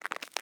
Ice Footstep 5.ogg